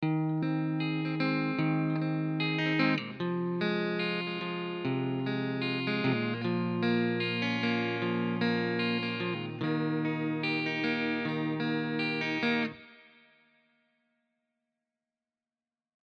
chiqui-audio---strato-wet-arpe.mp3